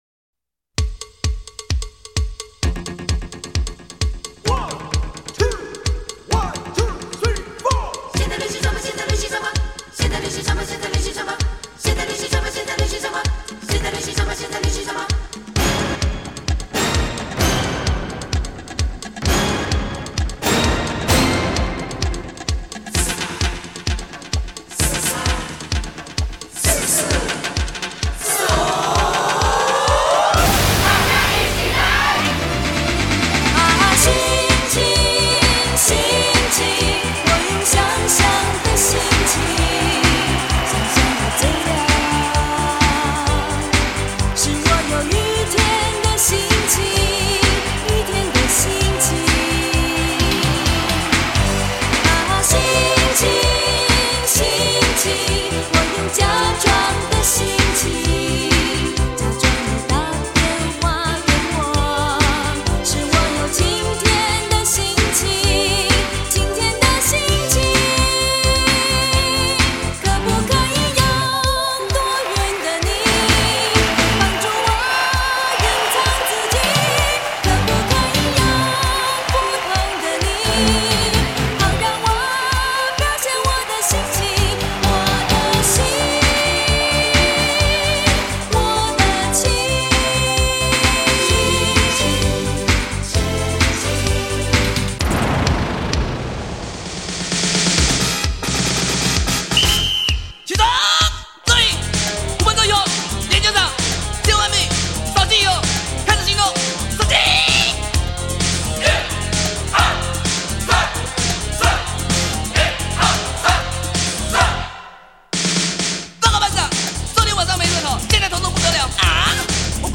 45就是 采45转快转的方式演唱串联当红歌曲的组曲 记录着70.80年代台湾流行乐史